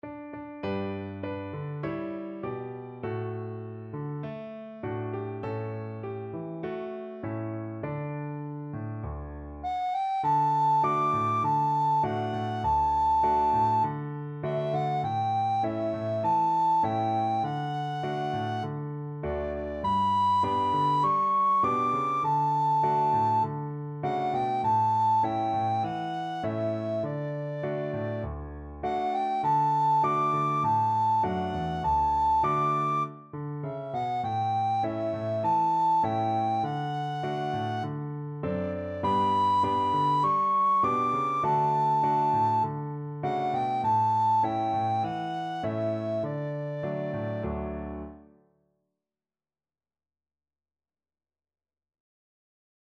Free Sheet music for Soprano (Descant) Recorder
D major (Sounding Pitch) (View more D major Music for Recorder )
4/4 (View more 4/4 Music)
Moderato
Traditional (View more Traditional Recorder Music)